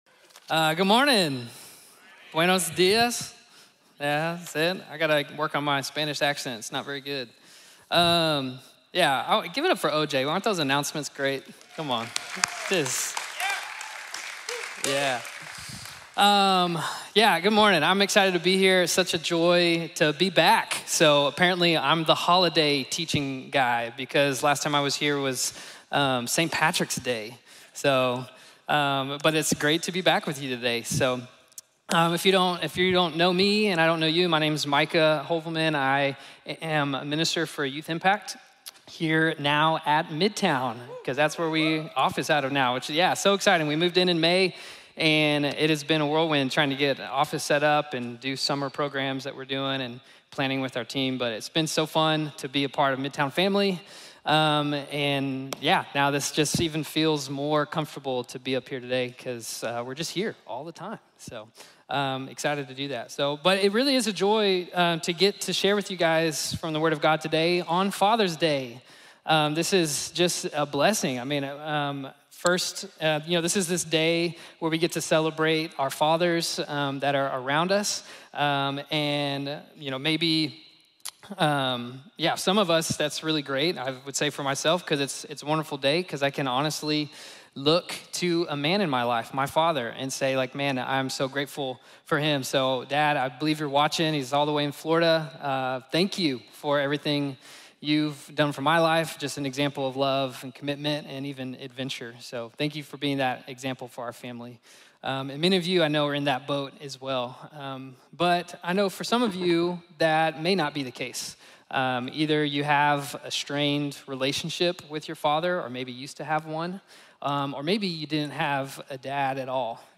Trusting God in the Gap | Sermon | Grace Bible Church